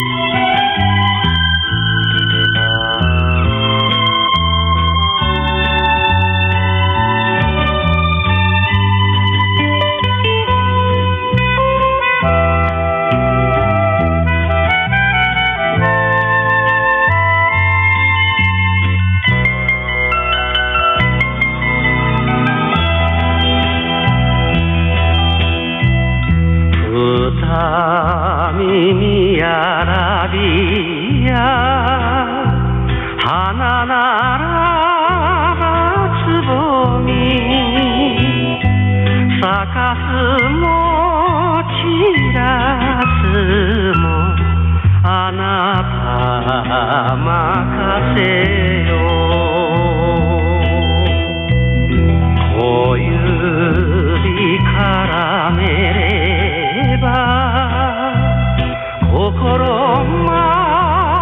受信音を録音してみました。
いずれもANT端子信号レベル＝約S9+20ｄBで、AGC-VR＝若干下げている。
②　送信モード＝LSB、帯域＝3.5KHｚ　 　受信機モード＝LSB、LPF=3.6KHｚ
LSB受信録音＝1分間
LSB＝モノラル音、ISB=ステレオ音となります。